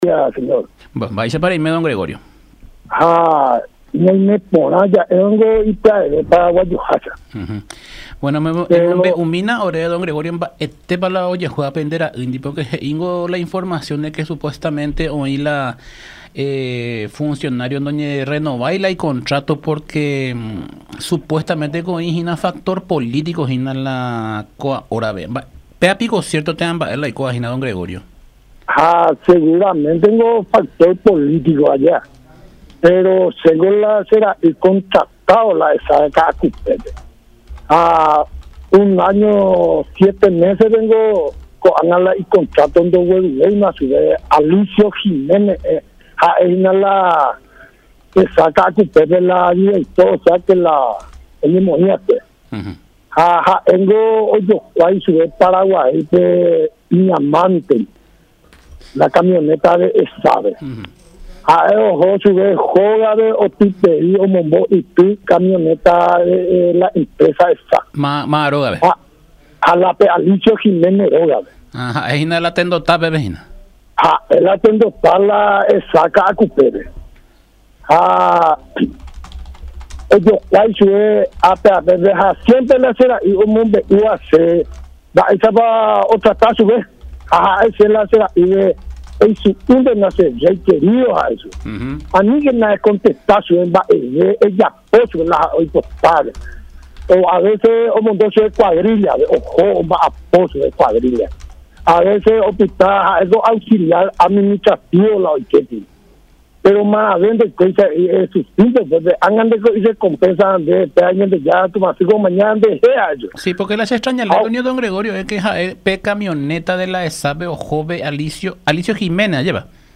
según explicó en diálogo con La Unión R800AM.